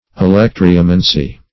Search Result for " alectryomancy" : The Collaborative International Dictionary of English v.0.48: Alectryomancy \A*lec"try*o*man`cy\, n. [Gr.